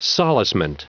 Prononciation du mot solacement en anglais (fichier audio)
Prononciation du mot : solacement